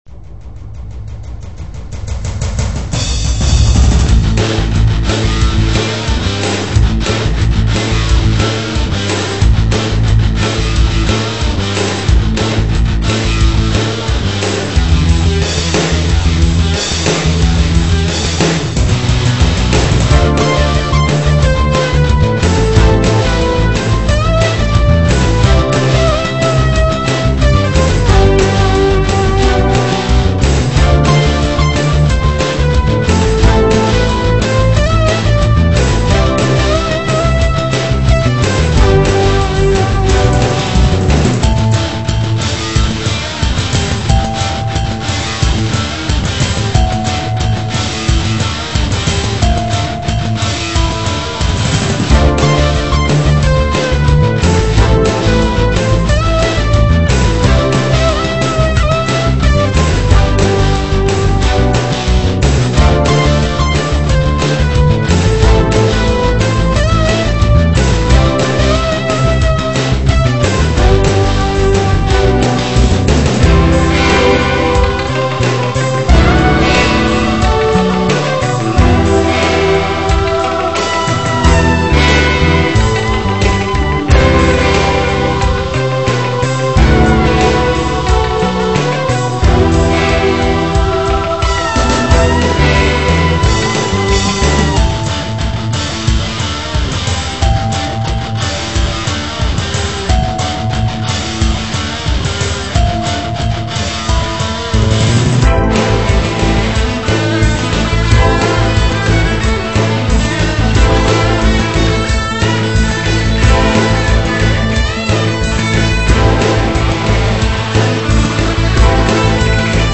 ロックな感じで、初めてのnewギター登場です。